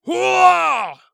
ZS长声3.wav
人声采集素材/男3战士型/ZS长声3.wav